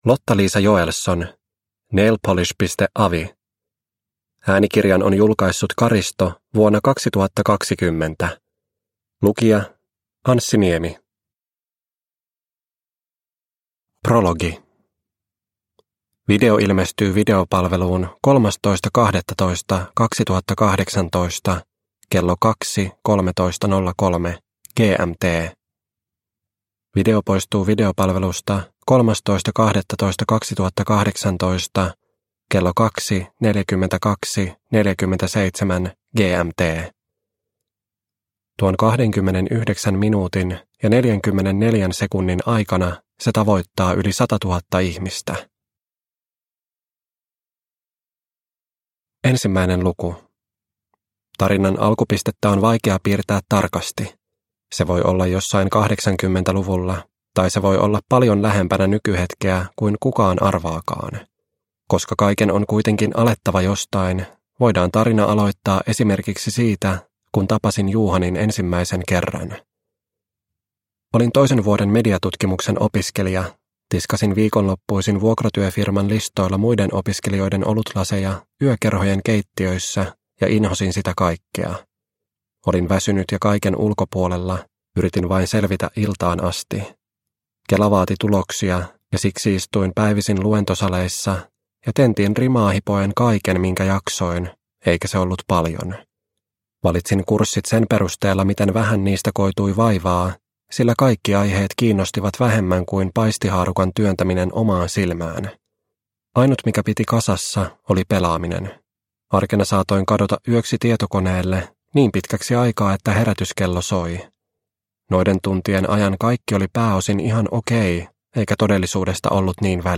Nailpolish.avi – Ljudbok – Laddas ner